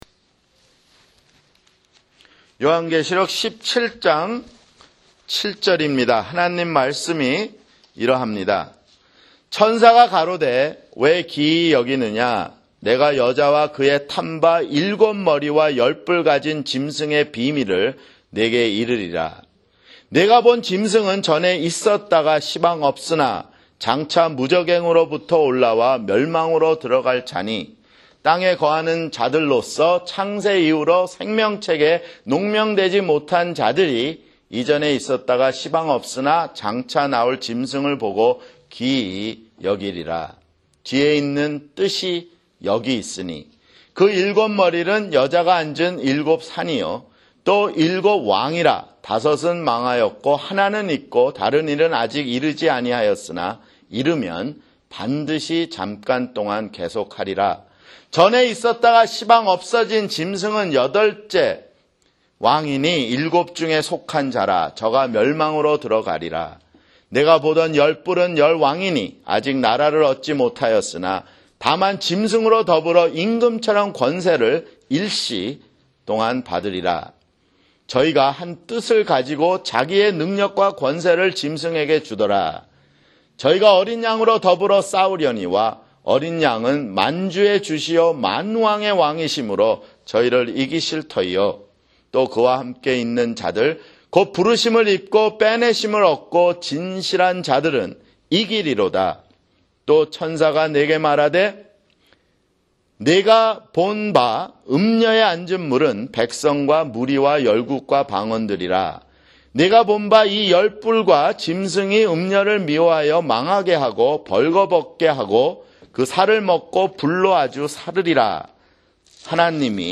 [주일설교] 요한계시록 (67)